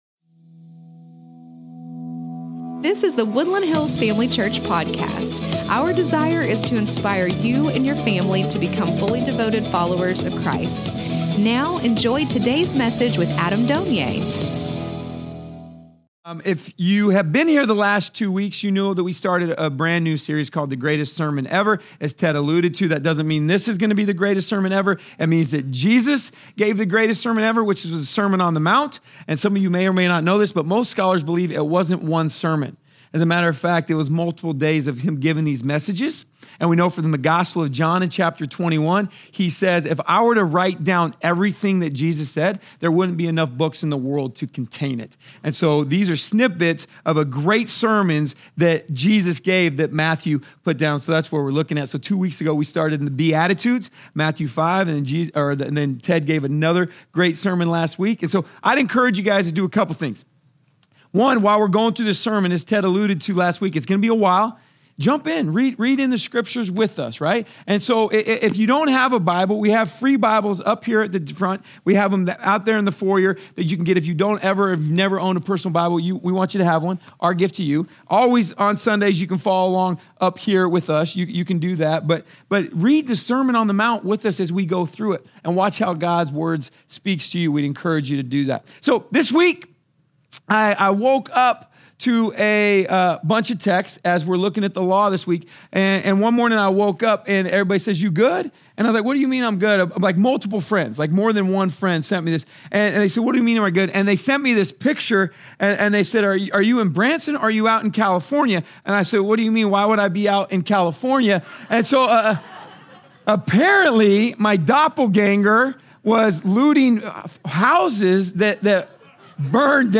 The Greatest Sermon Ever (Part 3) | Woodland Hills Family Church